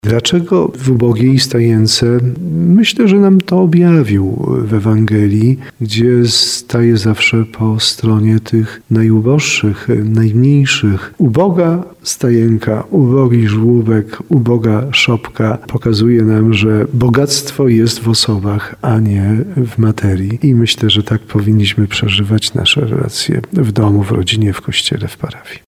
– Bóg będąc bogatym, stał się ubogim, aby nas swoim ubóstwem ubogacić – mówi biskup tarnowski Andrzej Jeż. 25 grudnia świętujemy uroczystość Narodzenia Pańskiego.